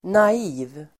Uttal: [na'i:v]